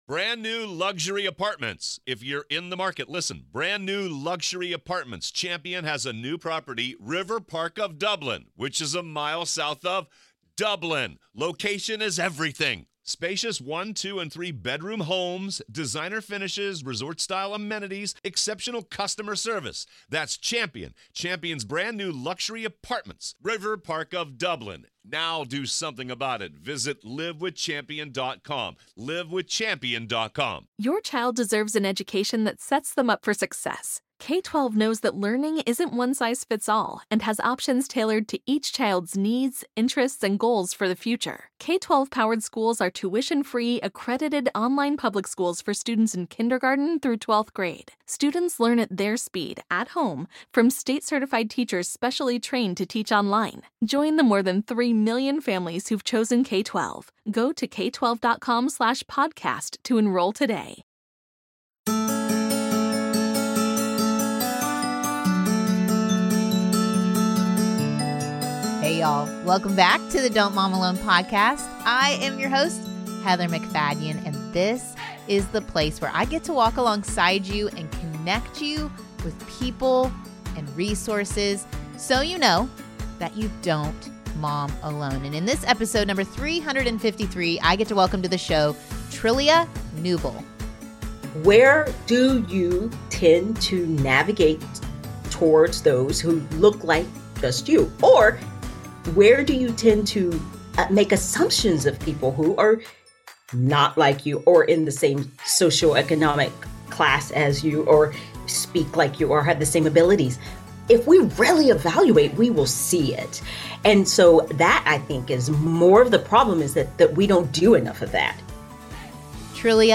Let’s have some “real talk” and think about our faith in action when it comes to our neighbors, even when they are different. This conversation will help you think about being more open and welcoming and train your children to do the same.